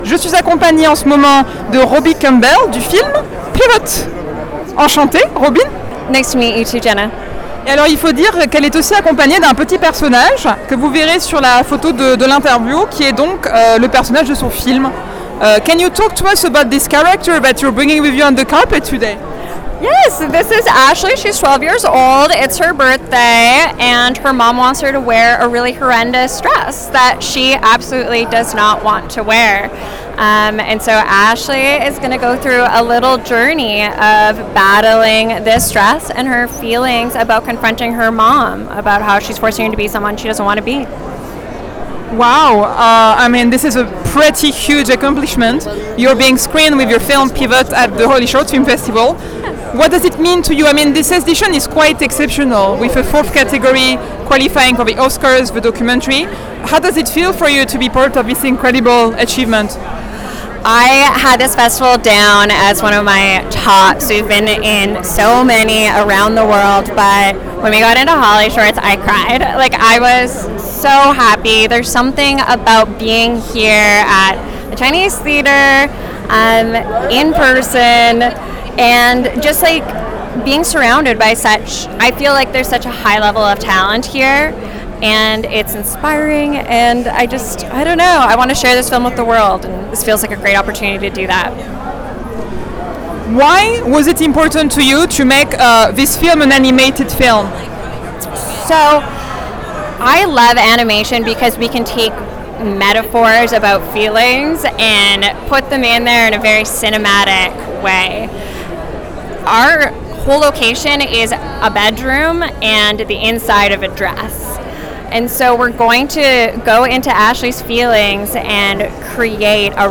Sur le tapis rouge du HollyShorts Film Festival 2013